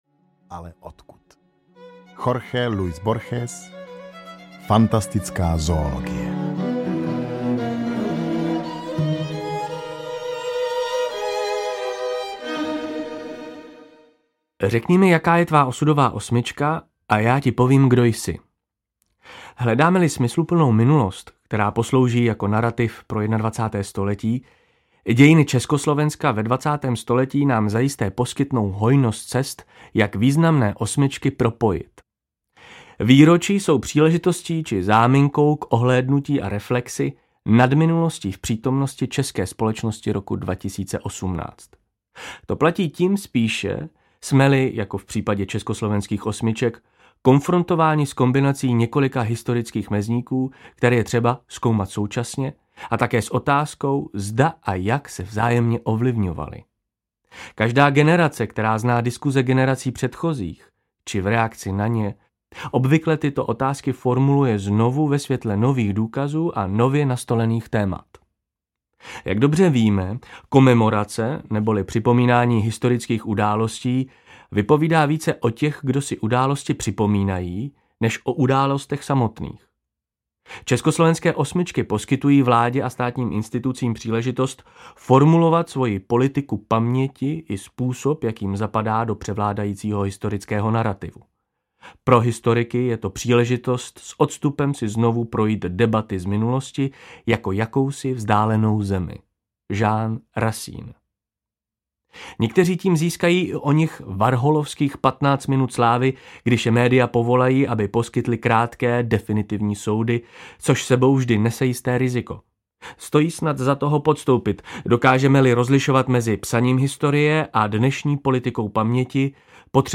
Střední Evropa je jako pták s očima vzadu audiokniha
Ukázka z knihy